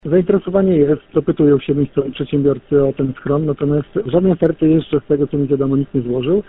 Mówi burmistrz Iwaszkiewicz i dodaje, że jeżeli w tym podejściu nie uda się oddać obiektu w dobre ręce, miasto nie zaniecha starań o tchnięcie w niego drugiego życia.